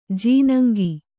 ♪ jīnaŋgi